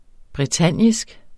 bretagnisk adjektiv Bøjning -, -e Udtale [ bʁεˈtanjisg ] Betydninger fra Bretagne; vedr. Bretagne eller bretagnerne